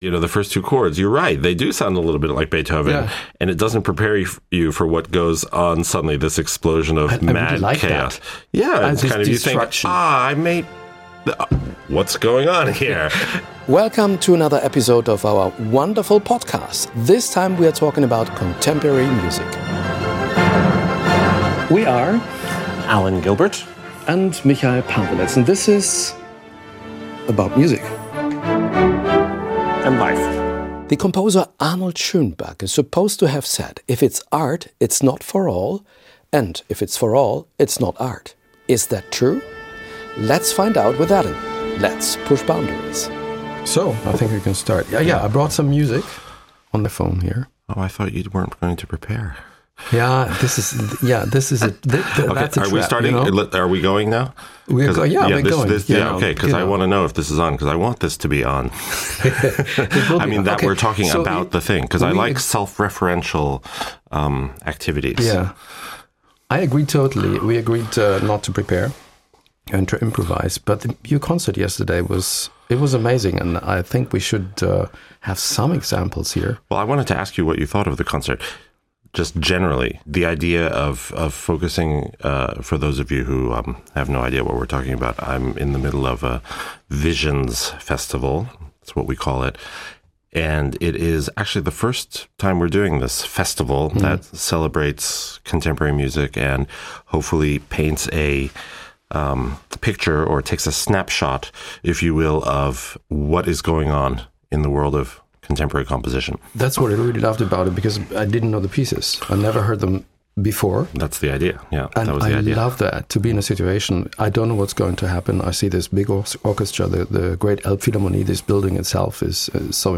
Beschreibung vor 2 Jahren Warum hat es zeitgenössische Musik oft schwer? Dirigent Alan Gilbert (NDR Elbphilharmonie Orchester) und Moderator Michail Paweletz (tagesschau) blicken in ihrem Podcast "about music" hinter die Kulissen des Klassik-Betriebs.